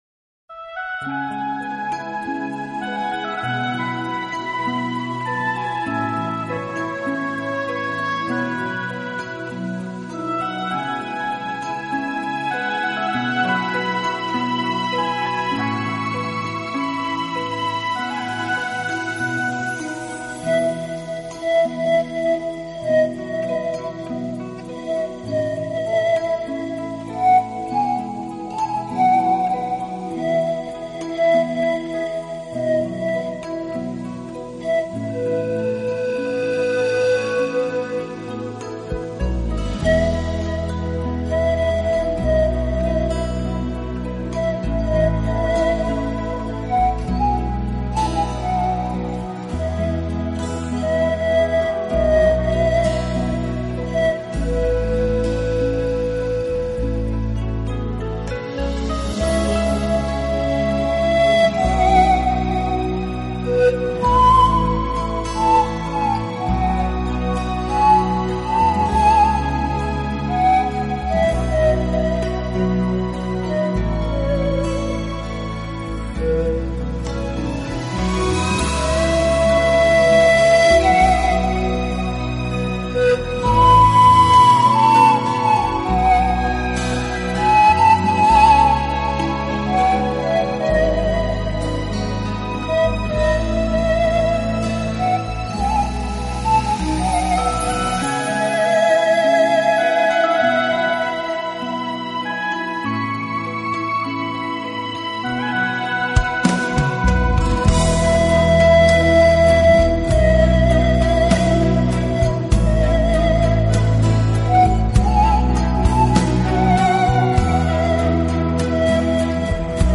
大名成为排箫的同义词，他的演奏將排箫清冷悠远的意境发挥到极致。
超广角音场的空间感演绎，大自然一尘不染的精华，仿佛让你远离凡尘嚣暄，